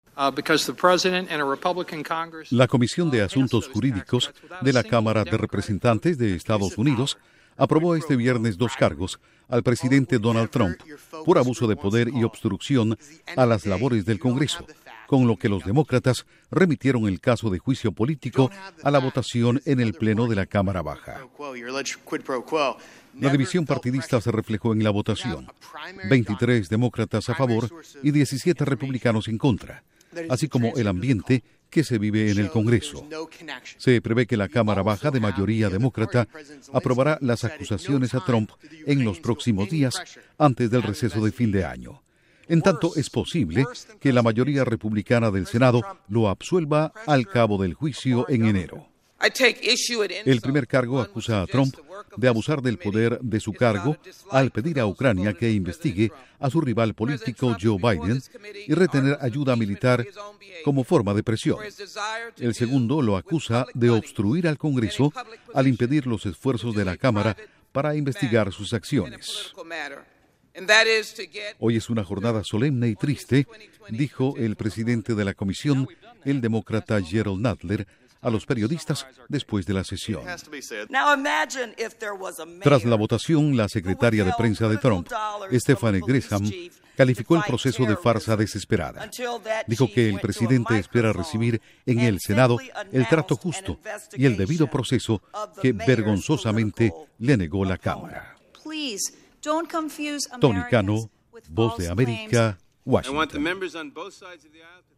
Duración: 1:44 Con audios del debate